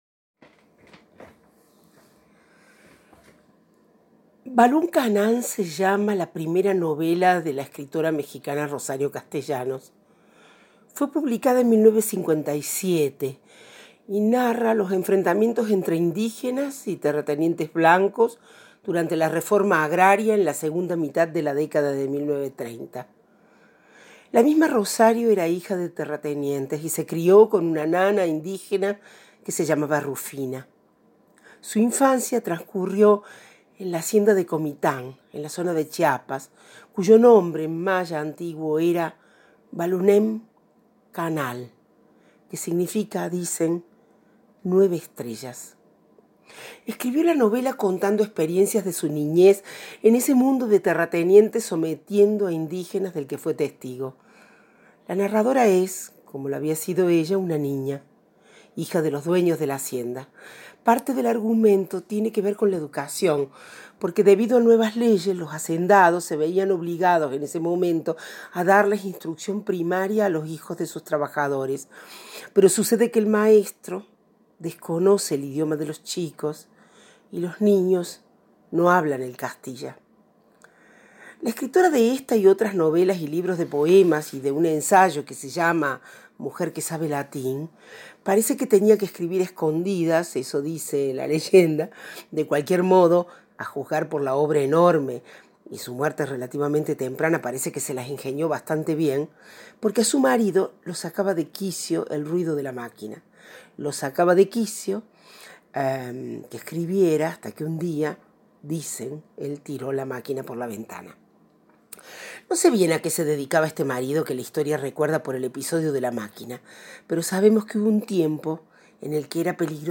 Aquí el audio para darse el gusto de oírlo de su boca: